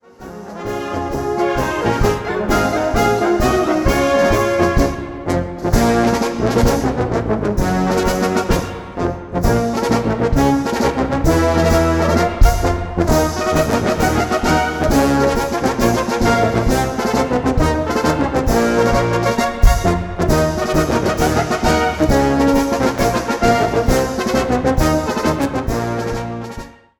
Live-Album zum Jubiläum
Blasmusik
Blasmusik auf höchstem Niveau.